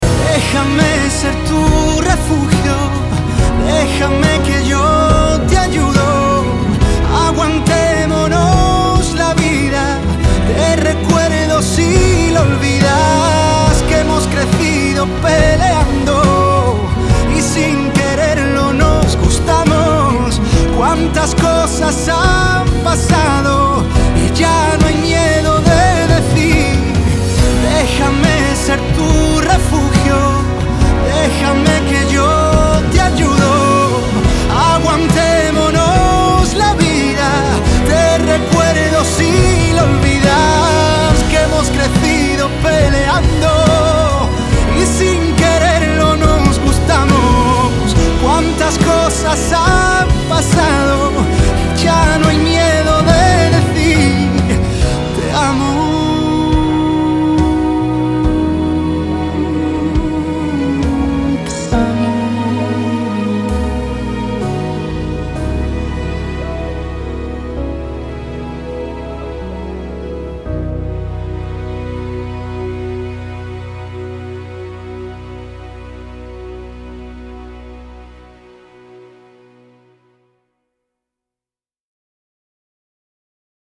• Качество: 320, Stereo
поп
мужской вокал
ballads
нежные
трогательные